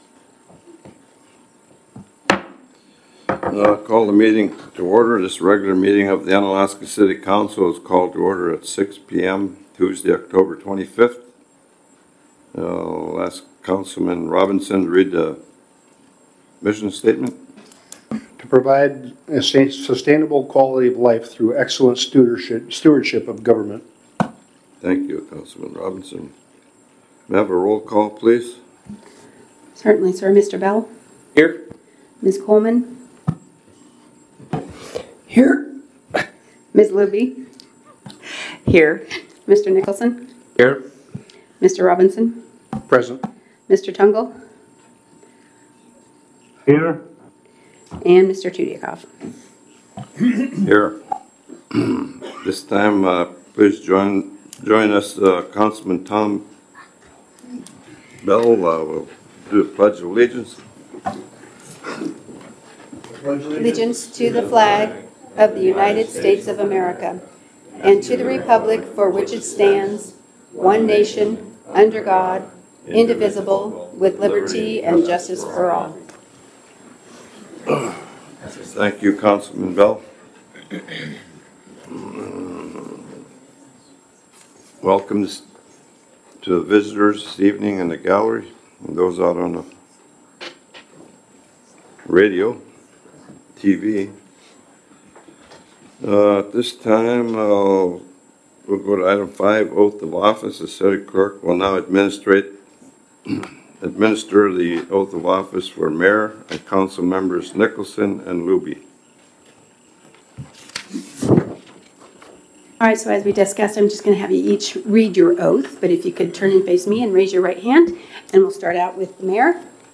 City Council Meeting - October 25, 2022 | City of Unalaska - International Port of Dutch Harbor